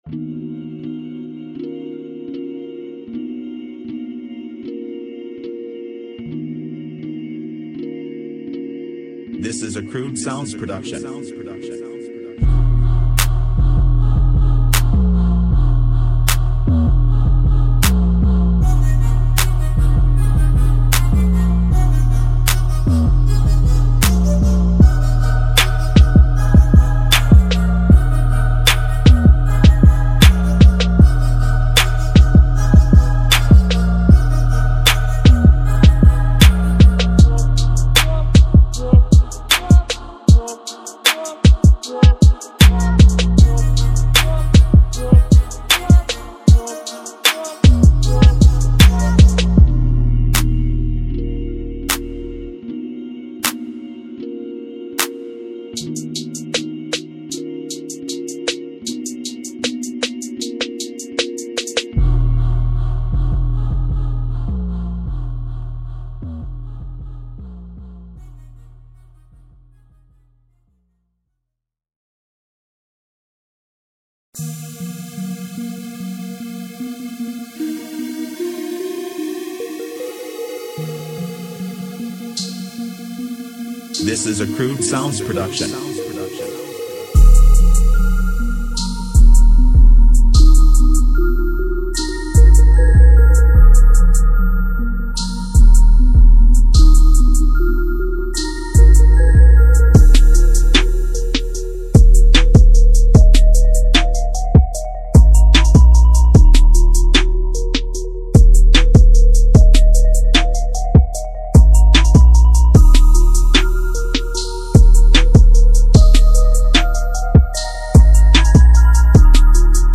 Hip HopMIDITrap
湿文件包含音频演示中听到的所有效果处理。